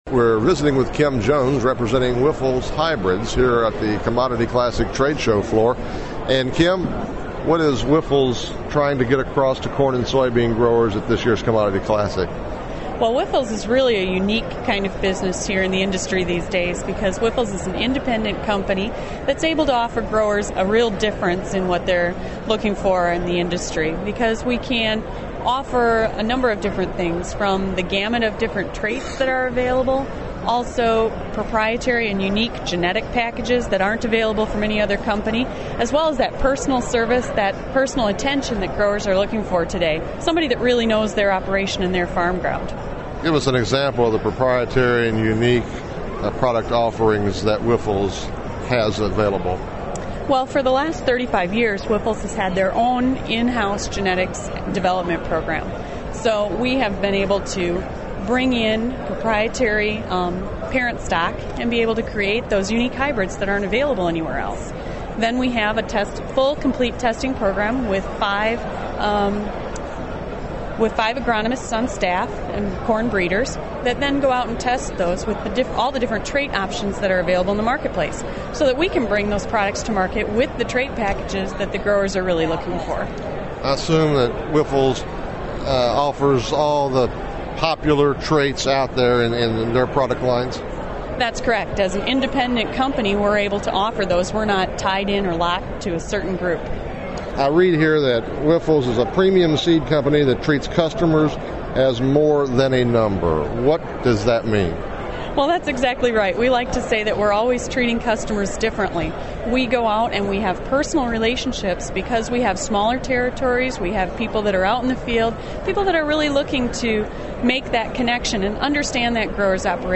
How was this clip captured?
Continuing the theme of their customers being “more than a number” Wyffels Hybrids was on display at Commodity Classic.